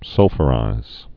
(sŭlfə-rīz, -fyə-)